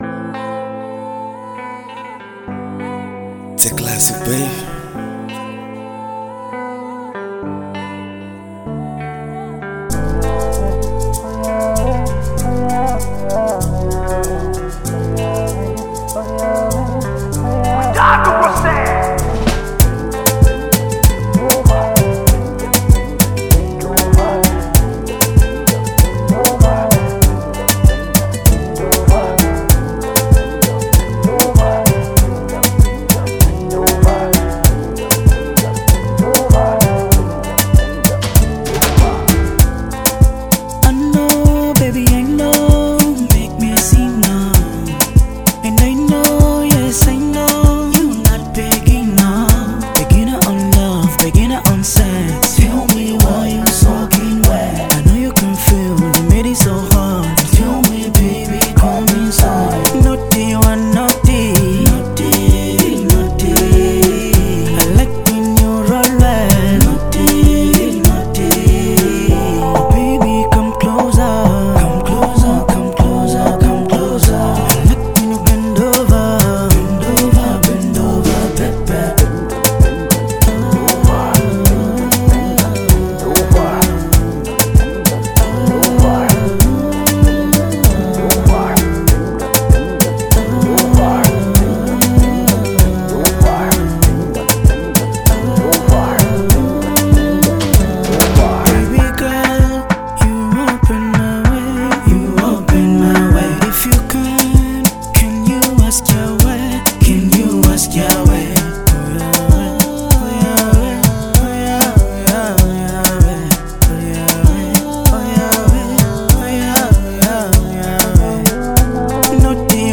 Afrobeat
a batida traz o sabor autêntico de Naija